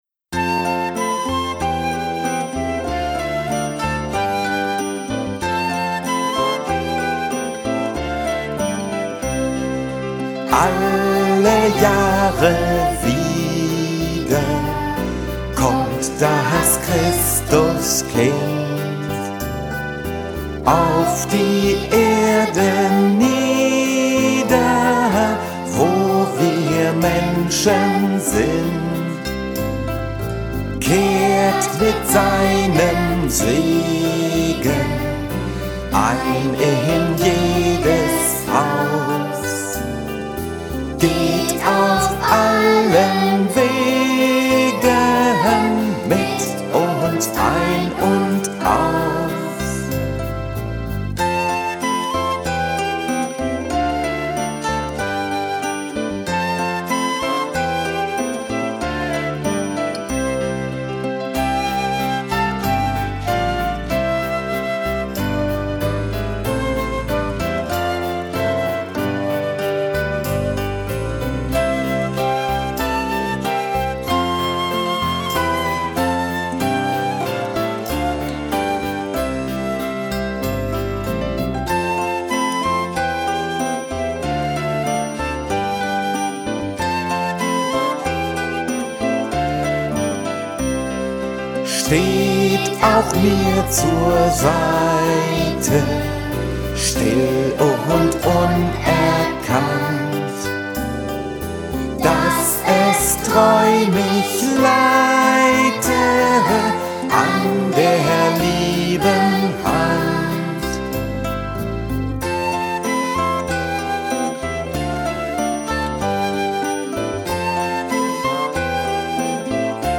Themenwelt Kinder- / Jugendbuch Gedichte / Lieder